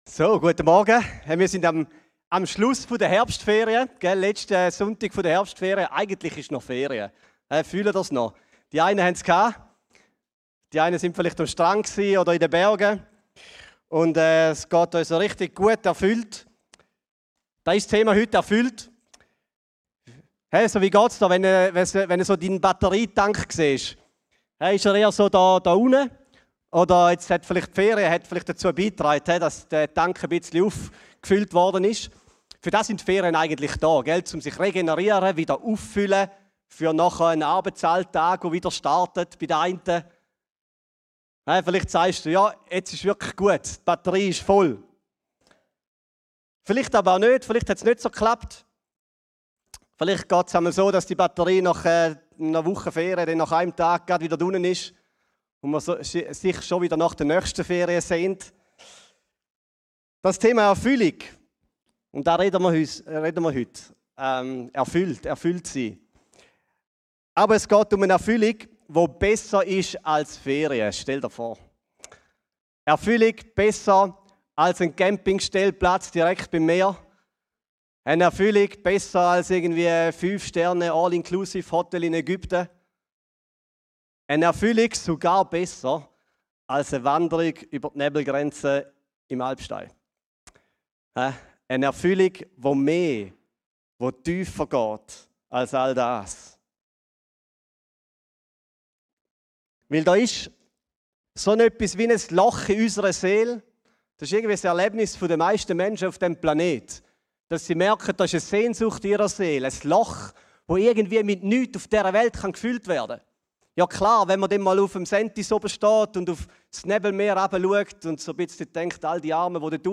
Die neueste Predigt